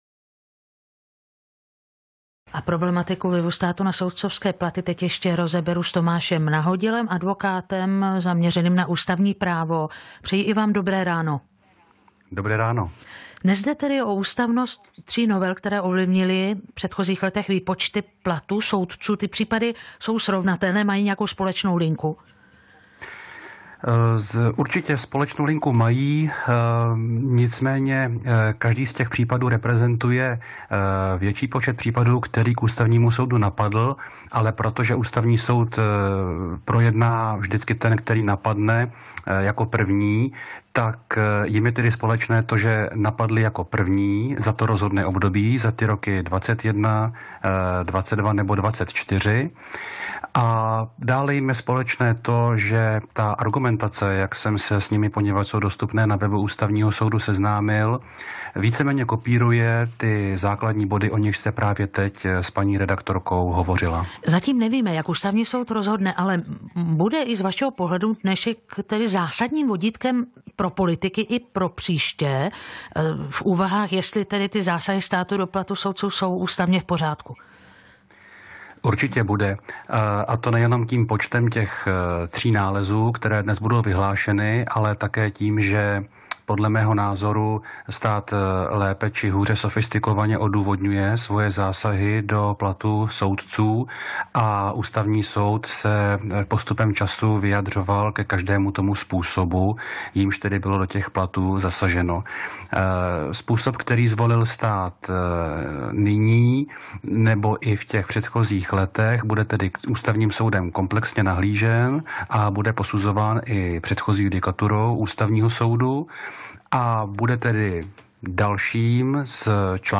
V rozhovoru pro ranní publicistický proud na Českém rozhlasu Plus hovořím o tom, odkdy a proč platí v právu zásada, že se na soudcovské plata nemá sahat.